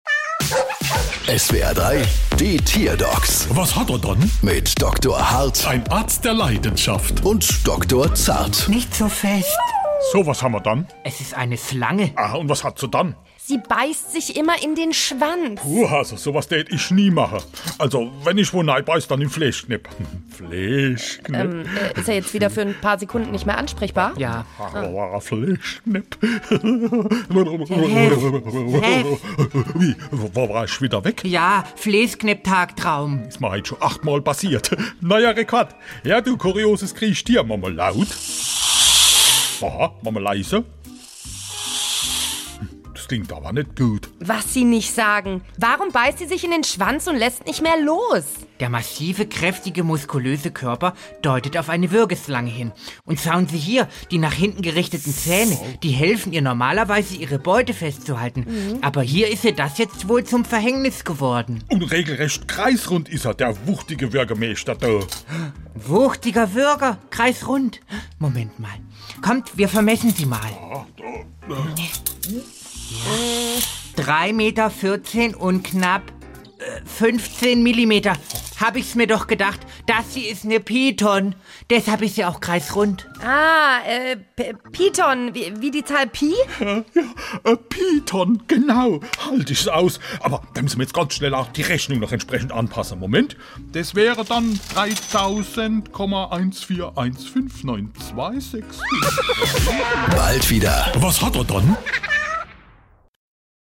SWR3 Comedy Die Tierdocs: Schlange beißt sich in den Schwanz